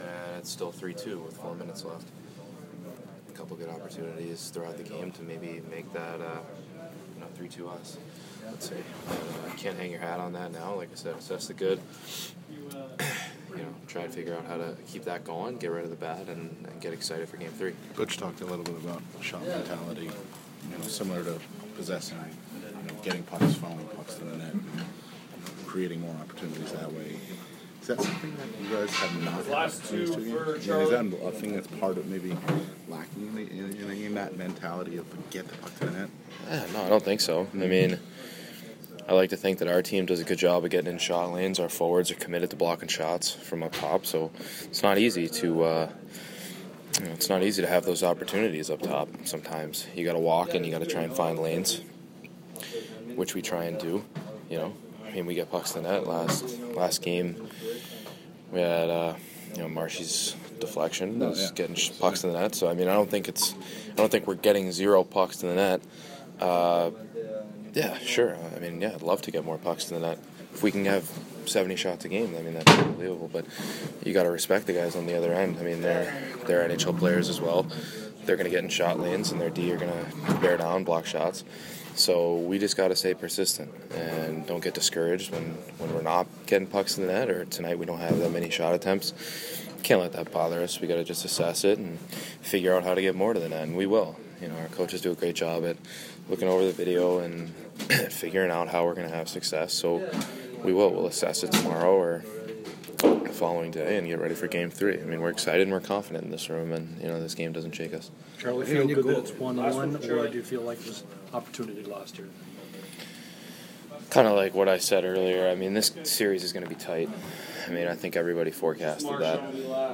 Charlie McAvoy post-game 4/30